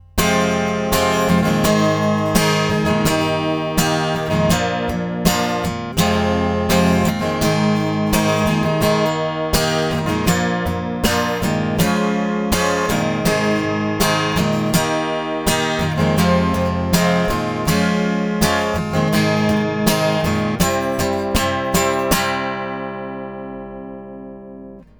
Kurzes Sample der Powerbridge über den Fishman-Preamp anbei - für Livezwecke eine tolle Lösung finde ich (man möge das Saitenscheppern und out-of-tune bitte ignorieren, Gitarre ist noch nicht wirklich eingestellt und ich hatte nur kurz Zeit die Gitarre und Preamp zu testen ). Anhänge ES-335 + Fishman.mp3 979,6 KB · Aufrufe: 145